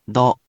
We’re going to show you the character, then you you can click the play button to hear QUIZBO™ sound it out for you.
In romaji, 「ど」 is transliterated as 「do」which sounds sort of like the English word 「dough」